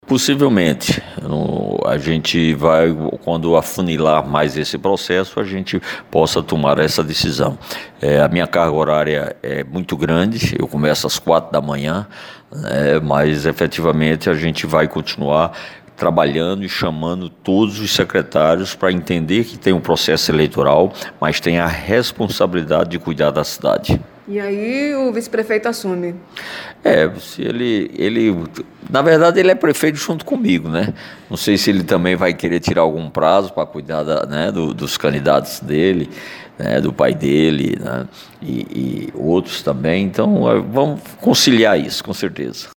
Os comentários de Lucena foram registrados nesta segunda-feira (15/08) pelo programa Correio Debate, da 98 FM, de João Pessoa.